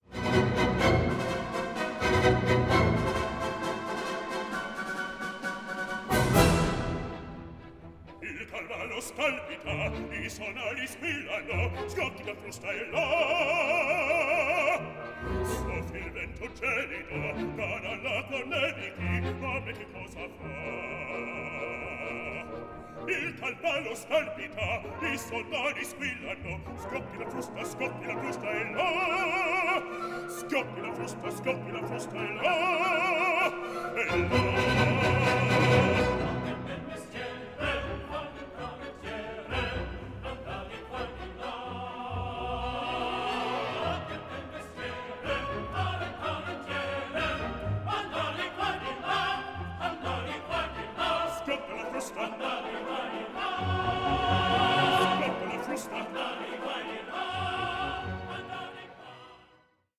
A SYMPHONIC APPROACH TO VERISMO